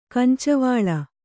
♪ kancavāḷa